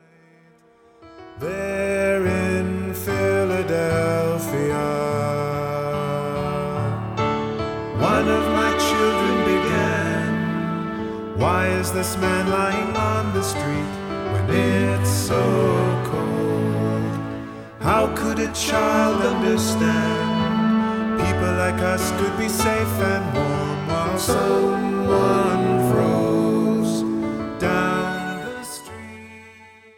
CJM (Contemporary Jewish Music)